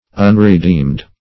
Unredeemed \Un`re*deemed"\, a.